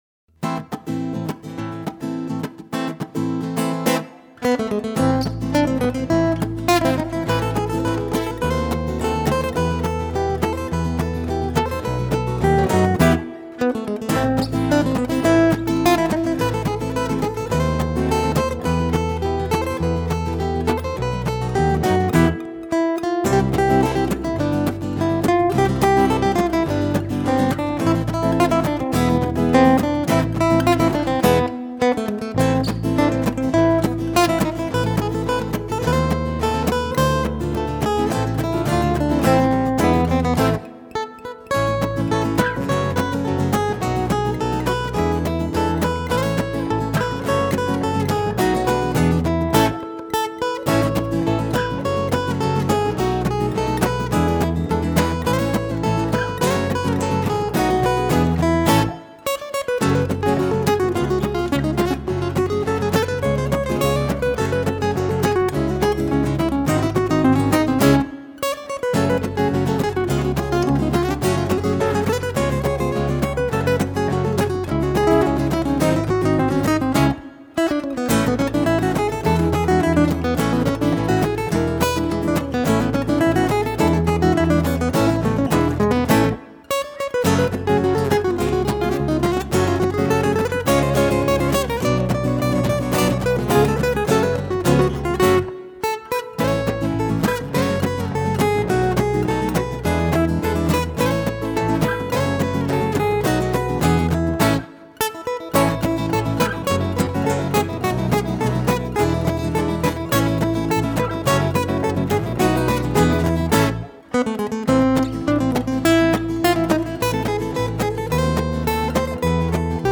Chitarra e Basso   Ascolta brano